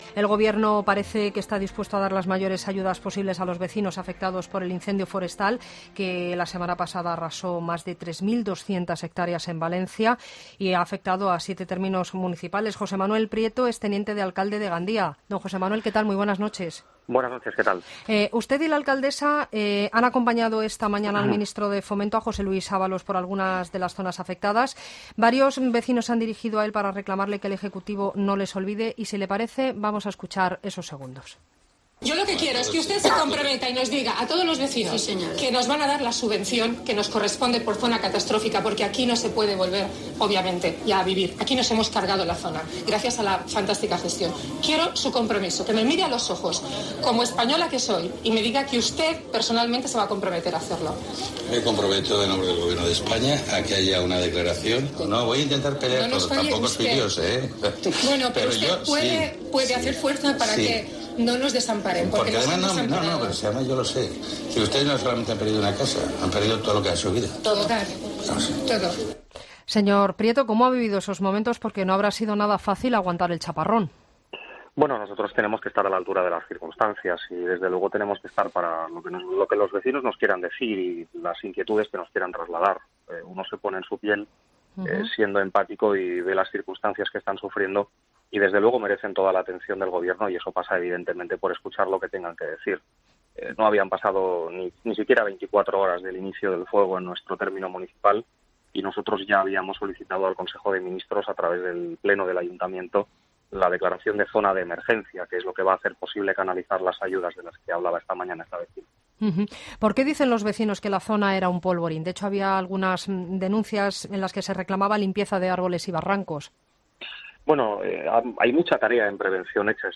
Entrevista a José Manuel Prieto, Teniente de Alcalde de Gandía en ‘La Linterna’, martes 14 de agosto de 2018